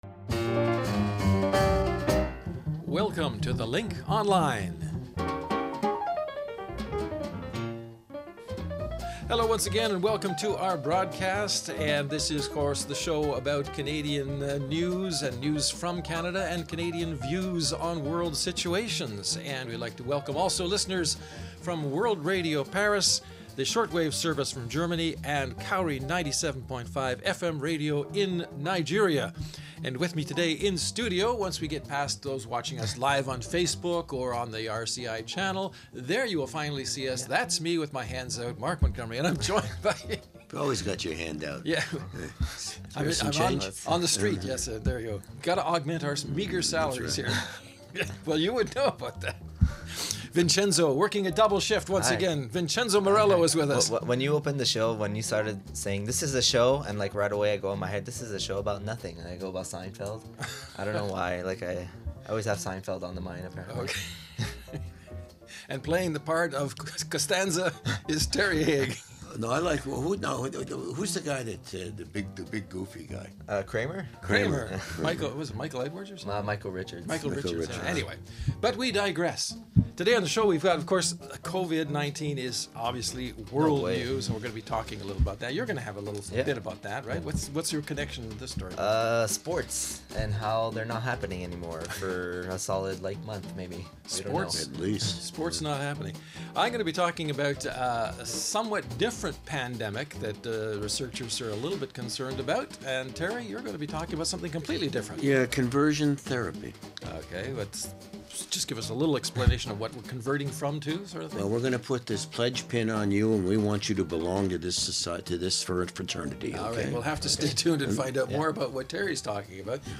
The Link podcast is a brief summary of just some of the many stories and interviews presented during the week with discussion on the stories along with occasional special guests, and features which are often about aspects of life in Canada.